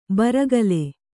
♪ baragale